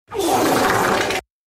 wet fard
wet-fart-made-with-Voicemod.mp3